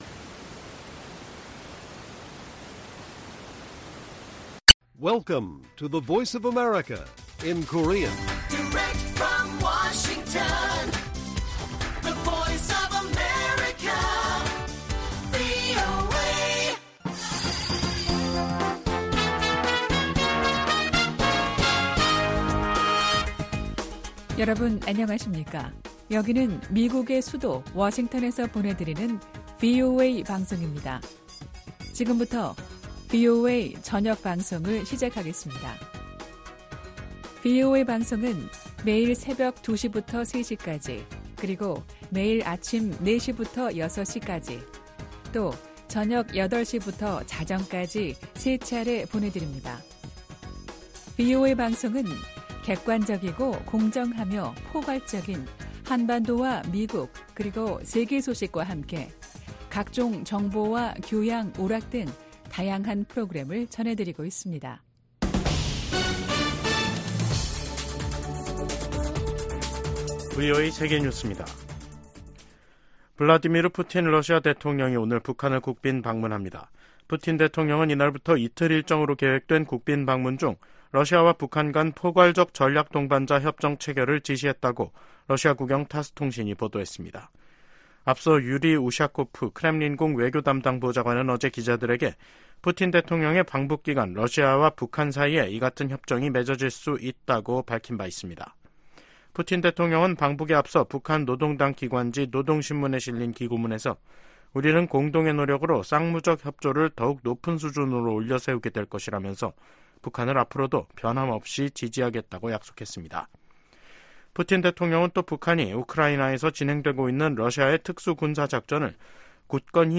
VOA 한국어 간판 뉴스 프로그램 '뉴스 투데이', 2024년 6월 18일 1부 방송입니다. 미 국무부는 블라디미르 푸틴 러시아 대통령의 방북과 두 나라의 밀착이 미국뿐 아니라 국제사회가 우려하는 사안이라고 지적했습니다. 미국의 전문가들은 러시아가 푸틴 대통령의 방북을 통해 탄약 등 추가 무기 지원을 모색하고 북한은 식량과 경제 지원, 첨단 군사기술을 얻으려 할 것이라고 분석했습니다.